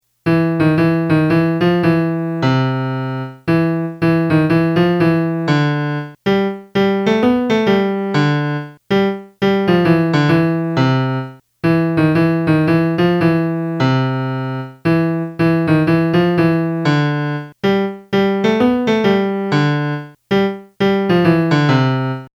spazzacamin-el-melody-2.mp3